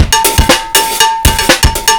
Index of /breakcore is not a good way to get laid/155BPM/silentkillerbreaks